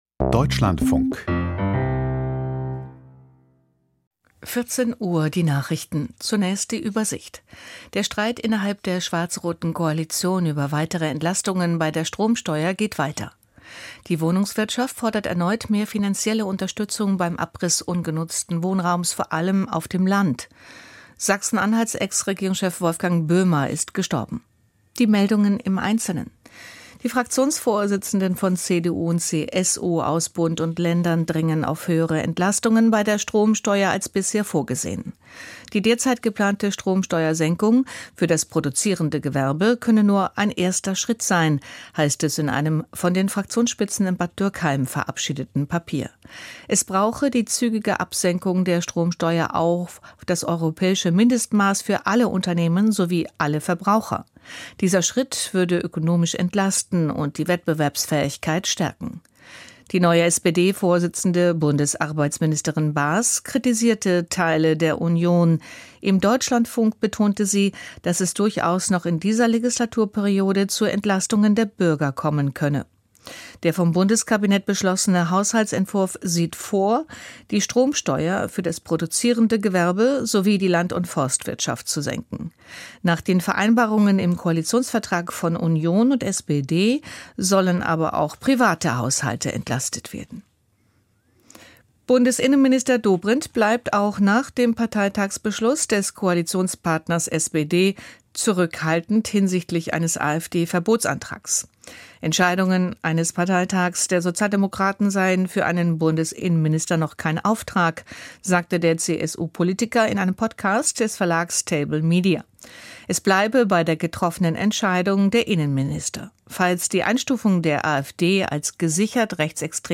Die Nachrichten vom 30.06.2025, 14:00 Uhr